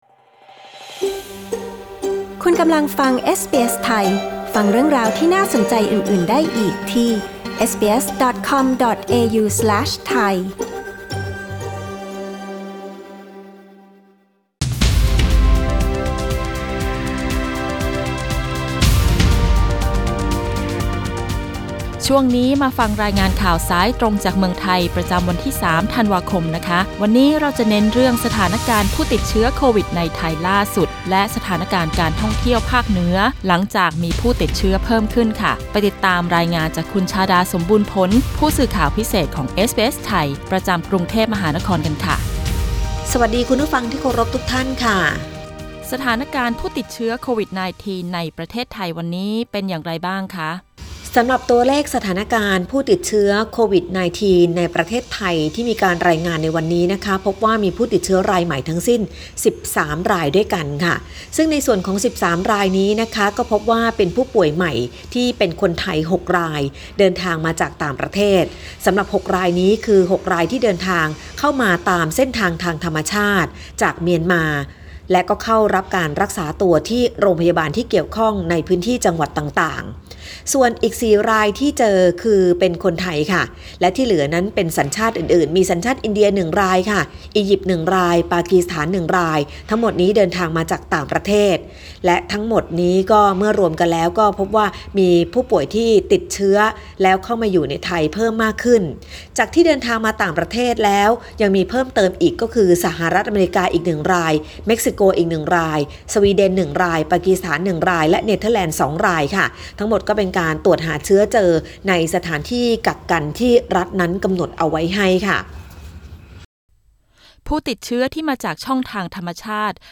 รายงานข่าวสายตรงจากเมืองไทย วันที่ 3 ธันวาคม 2563 Source: Pixabay